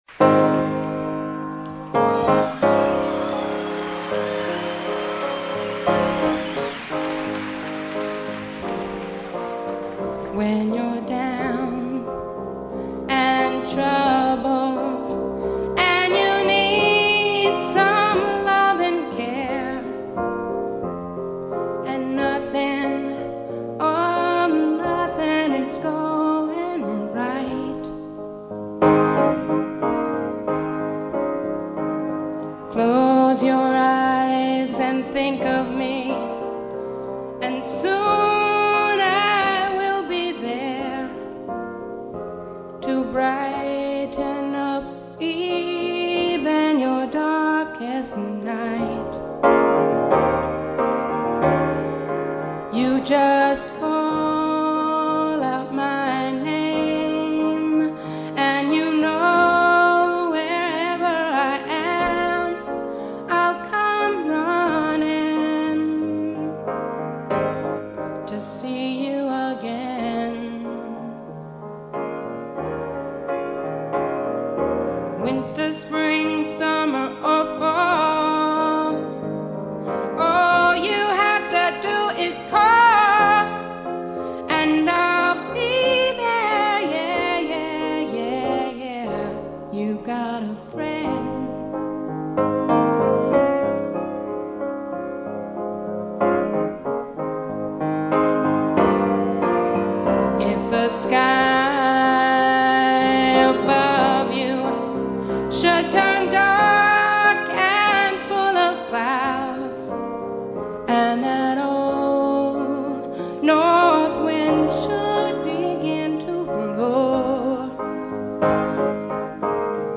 特别收录现场演唱钢琴伴奏版本。
专辑曲目现场版（Live）
[Piano-Voice Version]